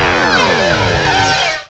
pokeemerald / sound / direct_sound_samples / cries / dusknoir.aif
-Replaced the Gen. 1 to 3 cries with BW2 rips.